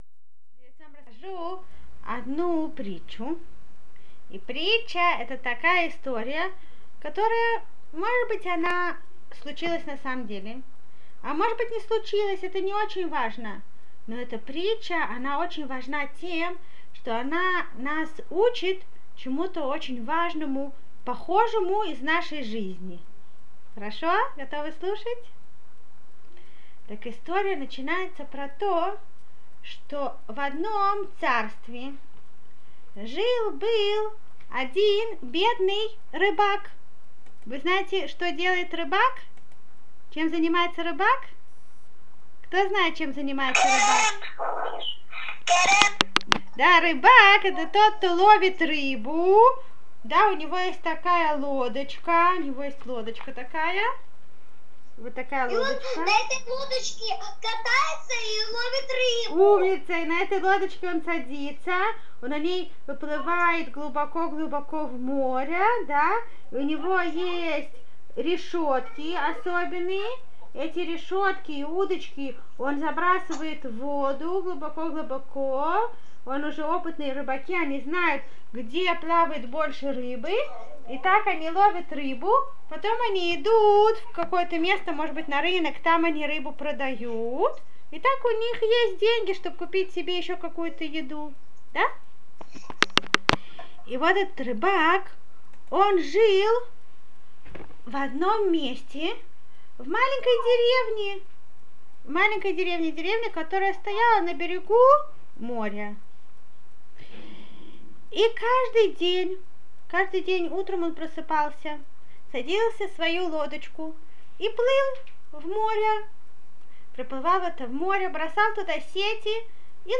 Притча – это рассказ со смыслом.